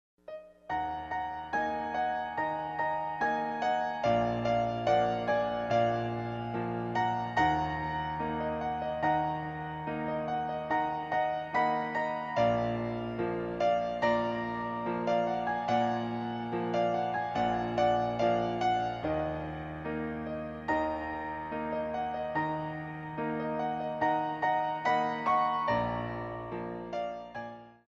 46 Piano Selections.